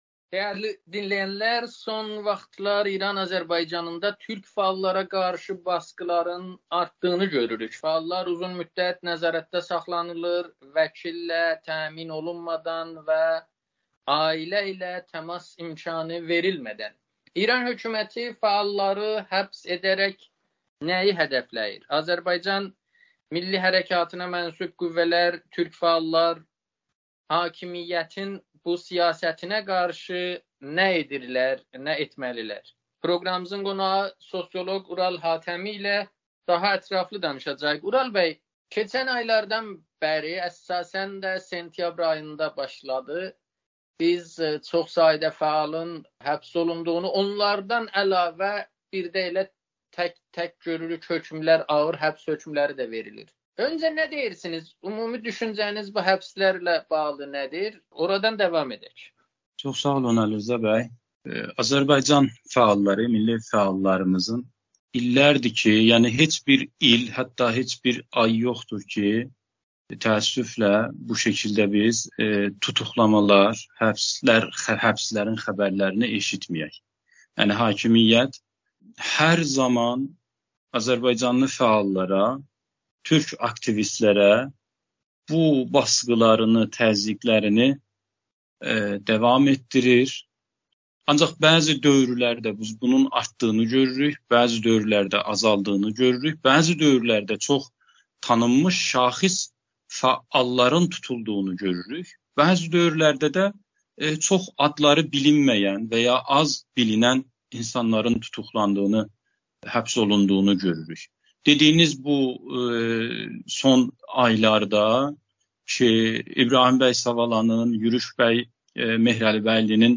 Amerikanın Səsinə müsahibədə